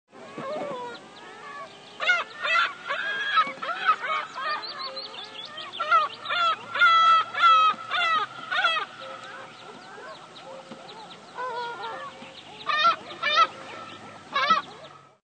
Gavià fosc (Larus fuscus)
larus-fuscus.mp3